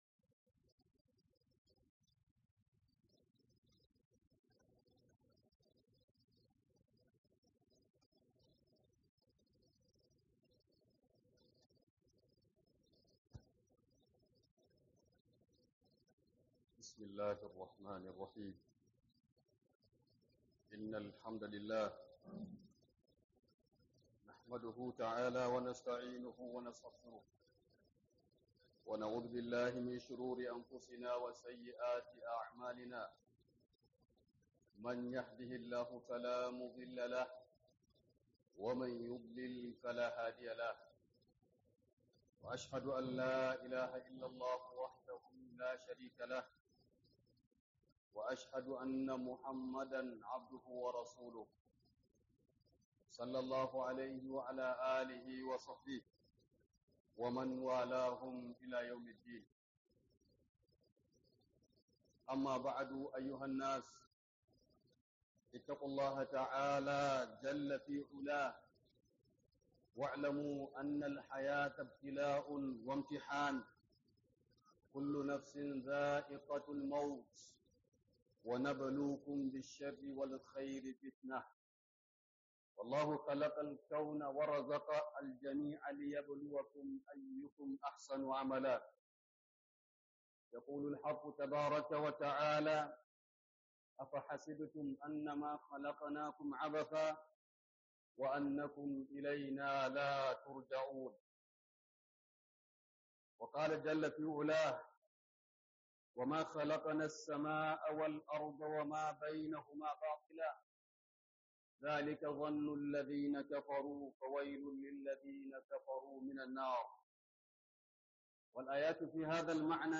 KHUDUBAR JUMU'A